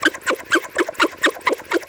cartoon_squeaky_cleaning_loop_03.wav